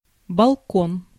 Ääntäminen
IPA: /bal.kɔ̃/